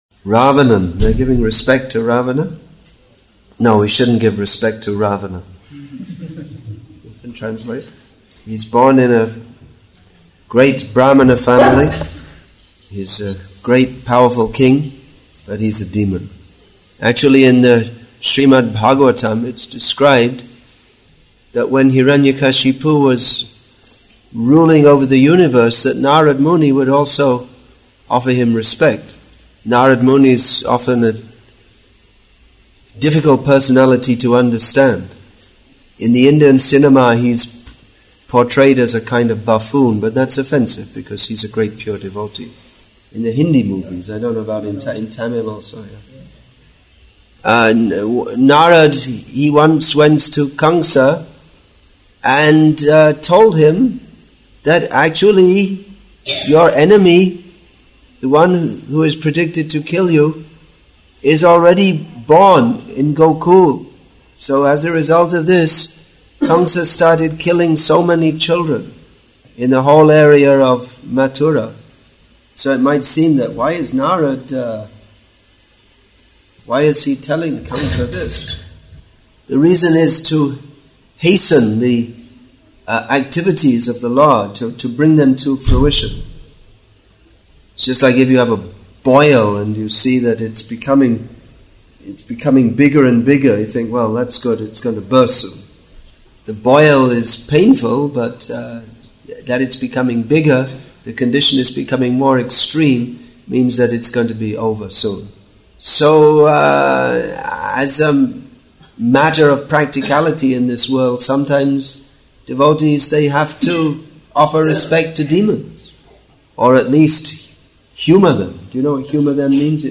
Preaching English with தமிழ் (Tamil) Translation; Vellore, Tamil Nadu , India